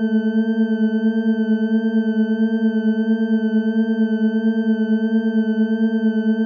add sound effects